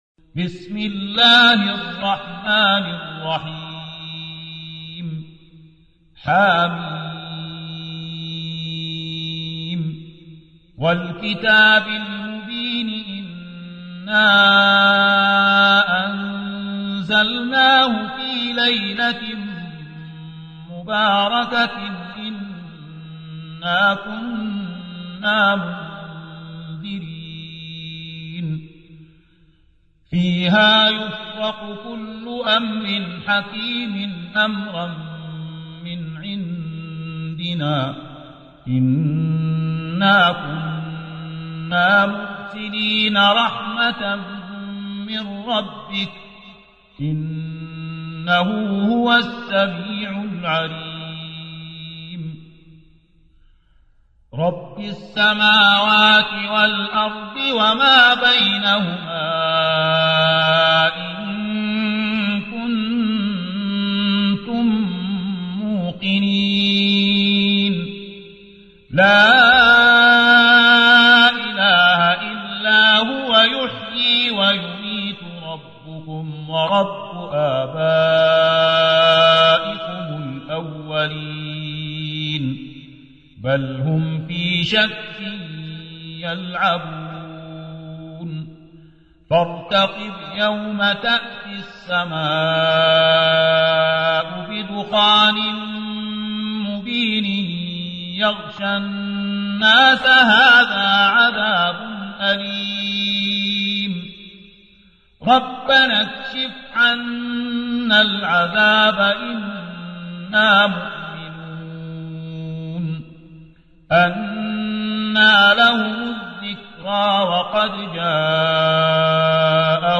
44. سورة الدخان / القارئ